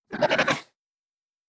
Monster_08_Attack.wav